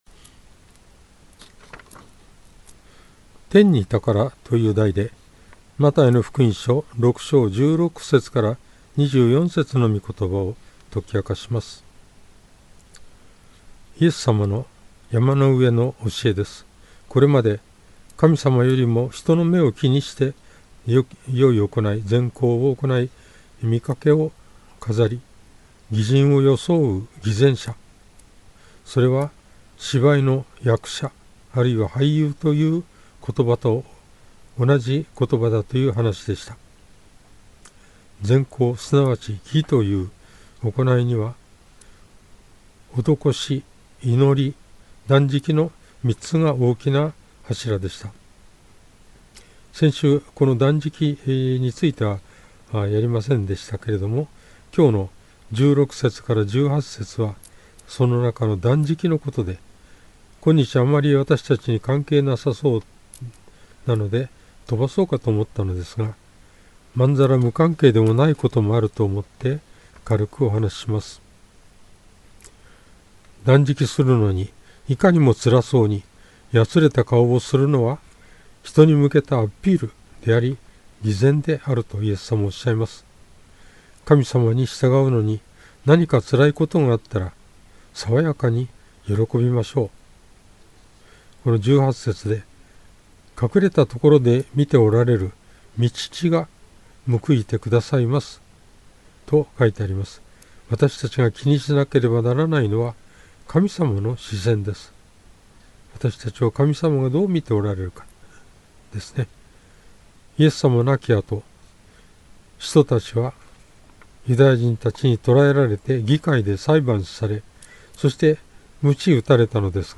主日礼拝
説教
♪ 当日の説教